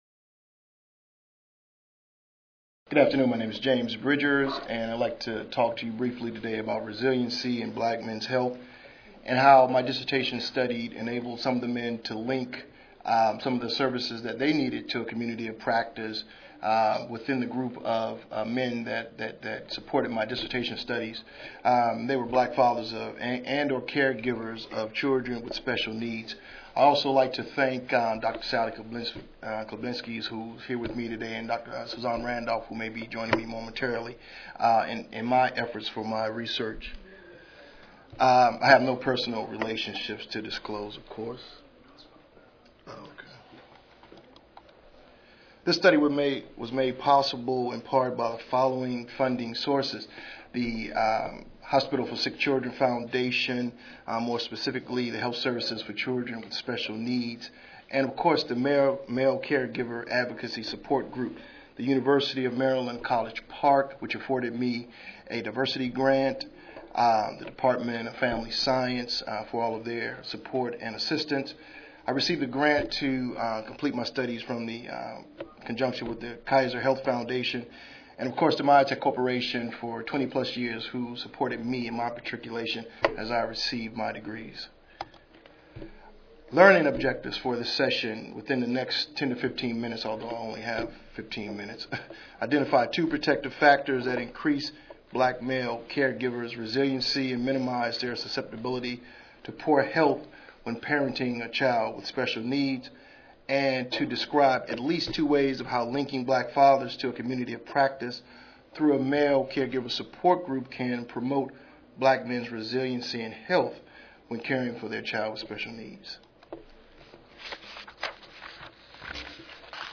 This presentation describes how Black men's health is affected by caring for special needs children.